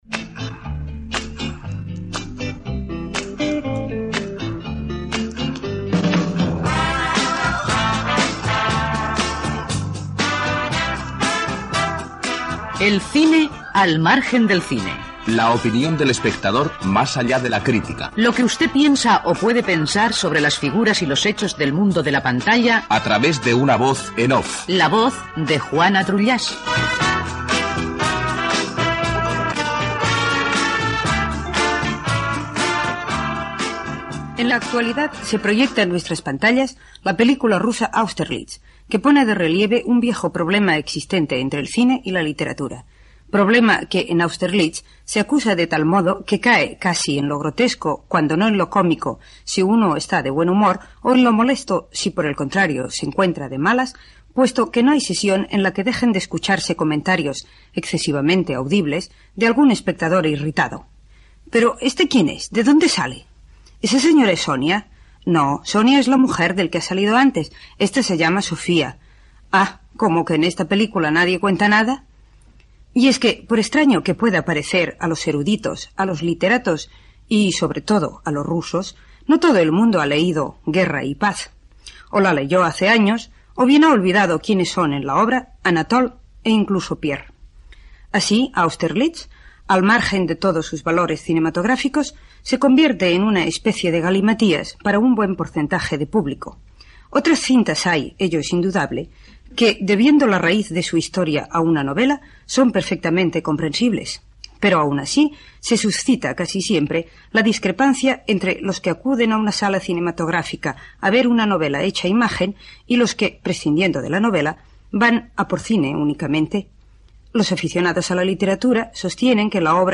Cultura